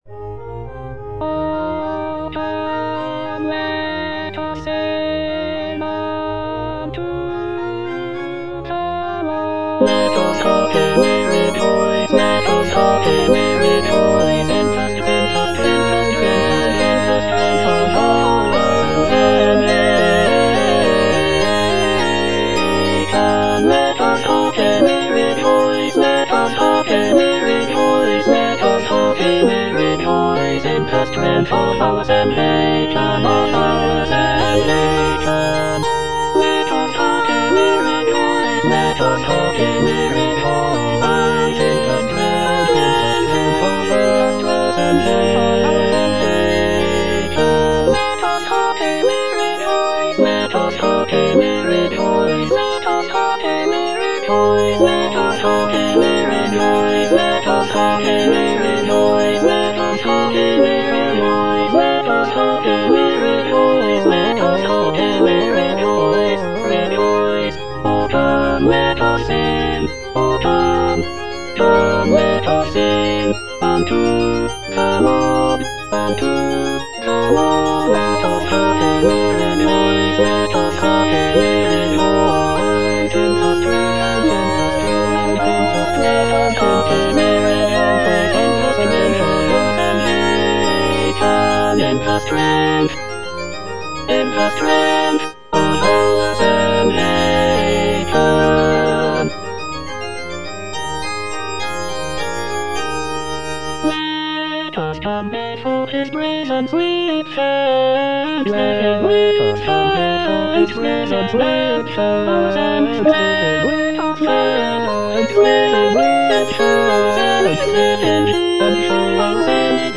(A = 415 Hz)
(All voices)